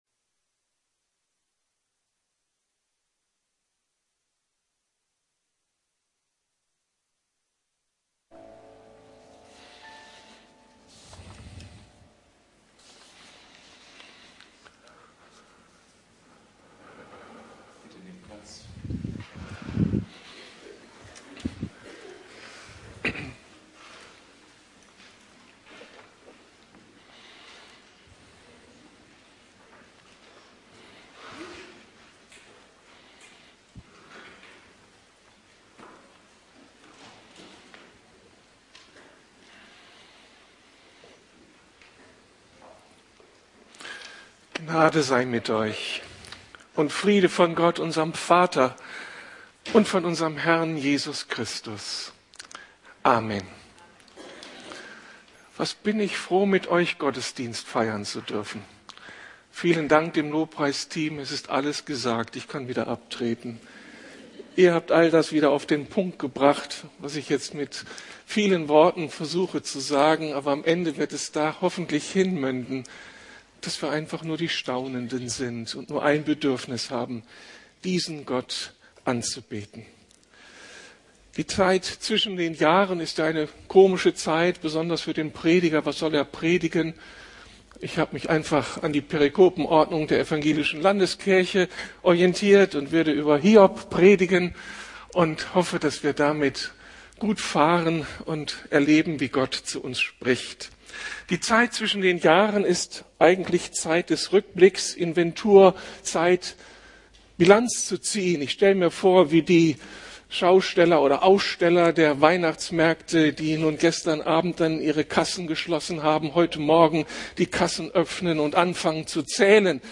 Aber nun hat mein Auge dich gesehen! ~ Predigten der LUKAS GEMEINDE Podcast